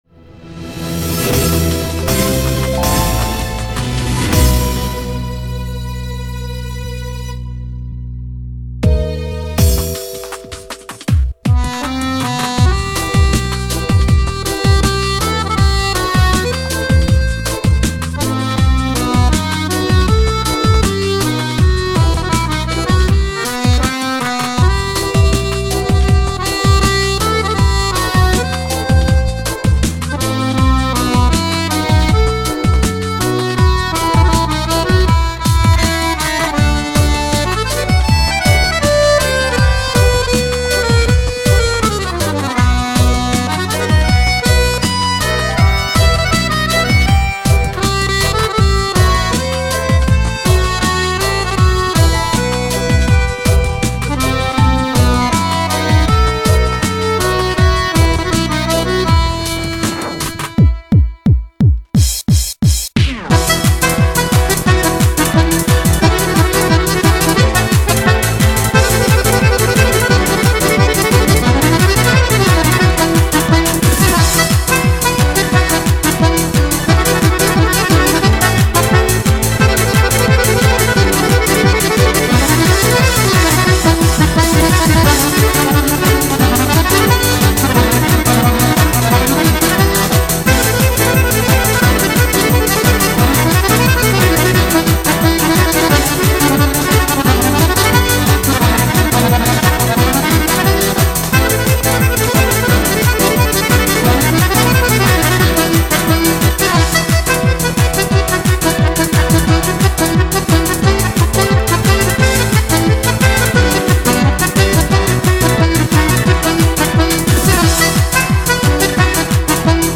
свой неповторимый стиль виртуозной игры на двух баянах